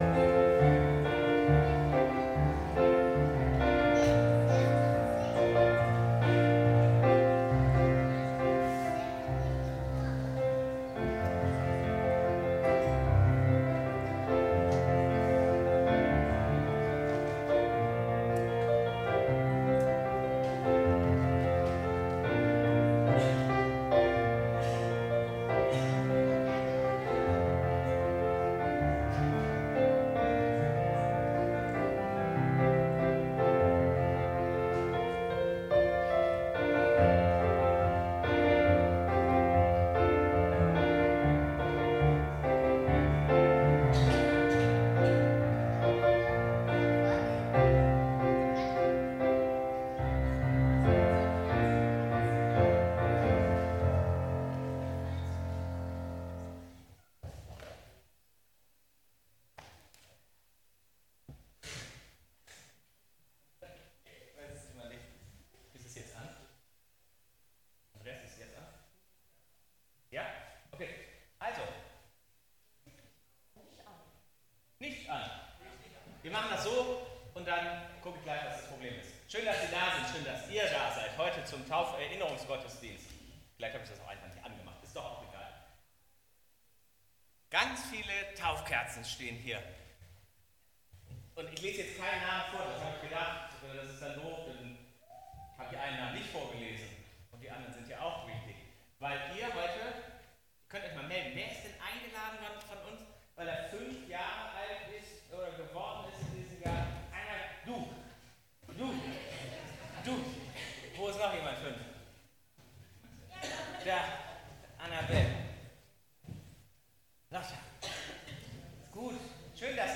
Gottesdienst - 12.10.2025 ~ Peter und Paul Gottesdienst-Podcast Podcast